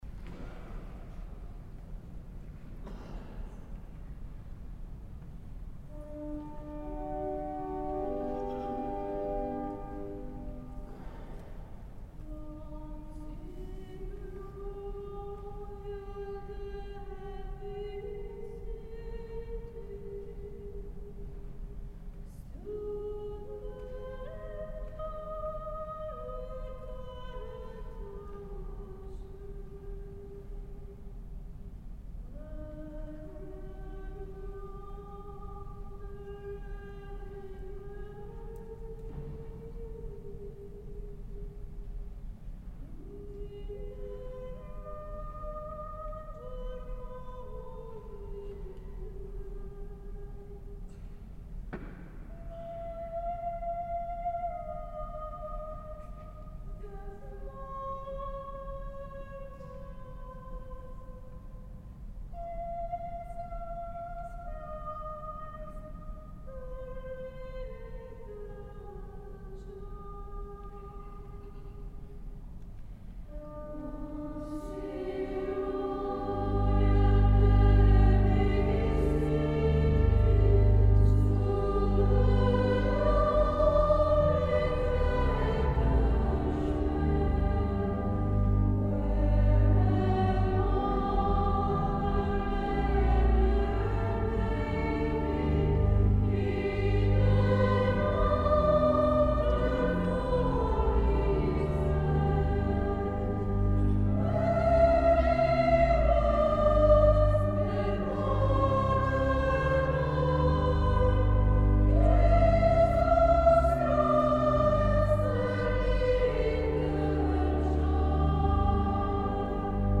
S. Gaudenzio church choir Gambolo' (PV) Italy
Domenica 9 dicembre ore 21.00    Chiesa di San Pietro Martire   Vigevano